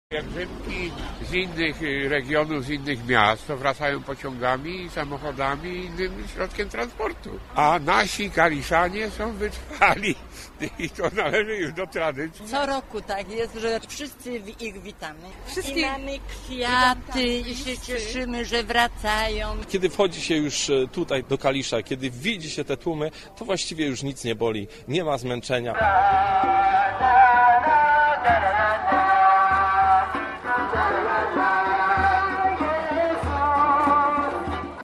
Mimo zmęczenia i upału moment powitania w Kaliszu jest niezwykle wzruszający: